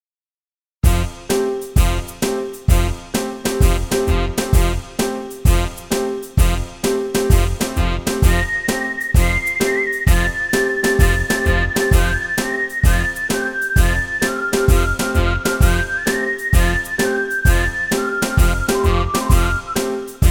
Rubrika: Pop, rock, beat
- směs
Solo Violin